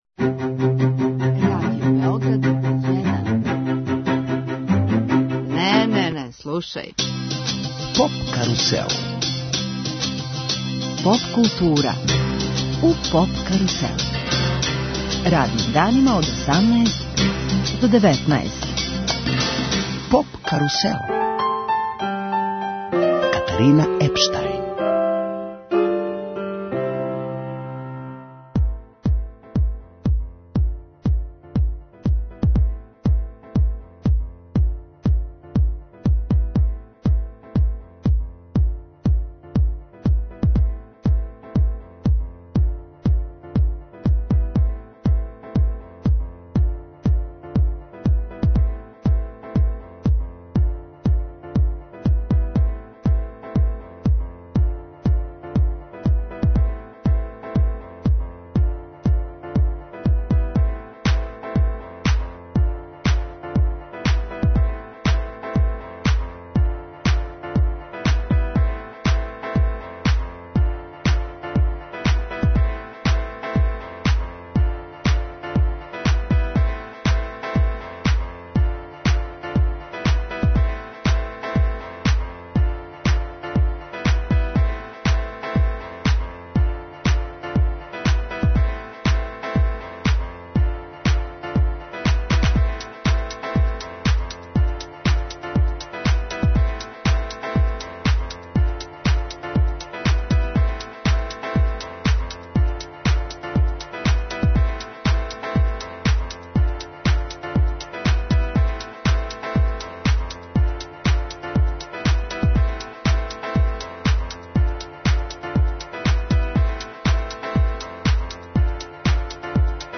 Двосатна специјална емисија посвећена је „Love фест-у“. Уживо, из Врњачке Бање, представићемо други по величини музички фестивал у Србији.